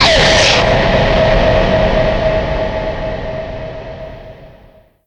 Título Efecto: ¡Qué susto!